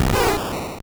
Cri de Bulbizarre dans Pokémon Or et Argent.